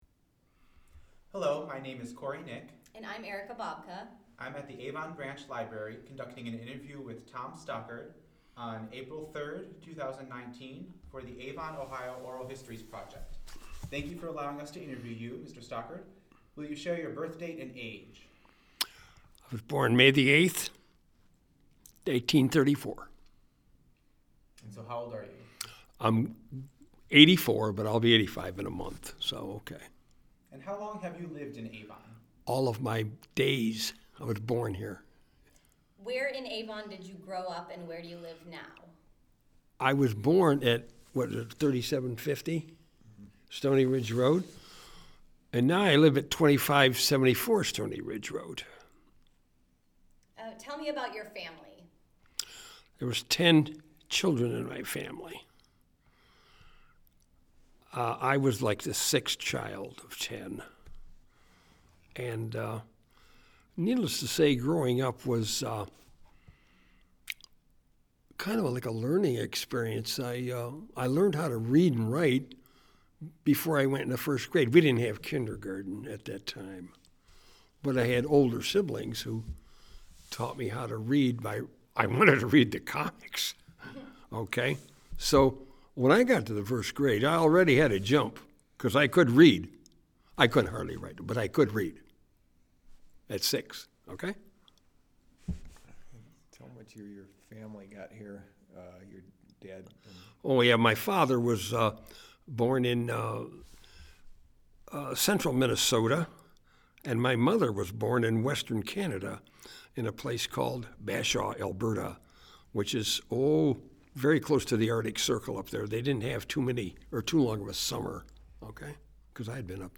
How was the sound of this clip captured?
Location: Avon Branch Library